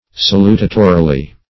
salutatorily - definition of salutatorily - synonyms, pronunciation, spelling from Free Dictionary
salutatorily - definition of salutatorily - synonyms, pronunciation, spelling from Free Dictionary Search Result for " salutatorily" : The Collaborative International Dictionary of English v.0.48: Salutatorily \Sa*lu"ta*to*ri*ly\, adv.